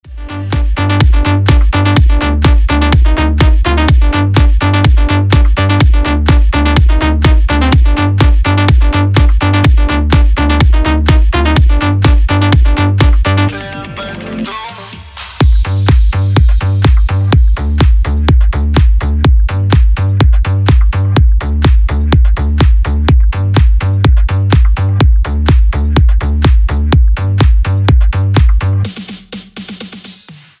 • Качество: 320, Stereo
качающие
electro house
ремиксы
Крутой ремикс трендовой песни 2021 года